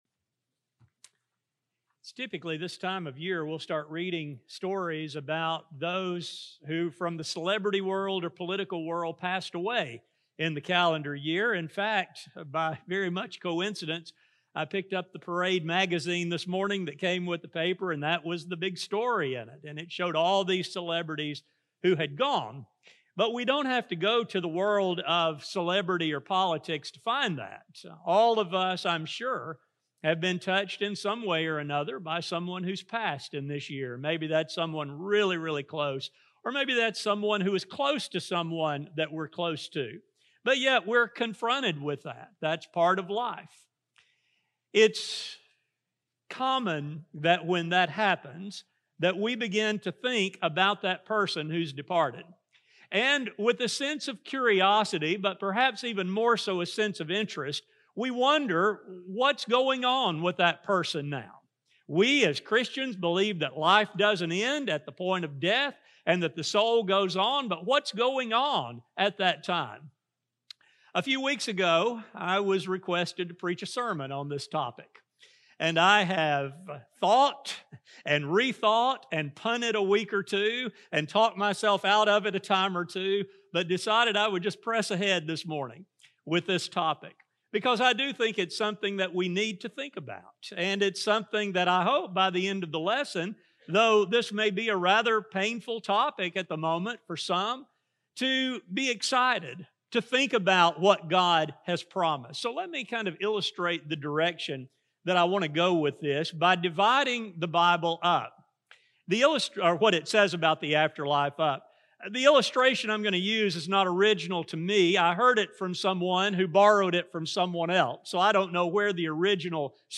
When these sad times occur, questions often arise in the mind concerning the location and state of the soul following death. In this study, we will see what the Bible teaches concerning both the time immediately following death, along with the promises made to the faithful. A sermon recording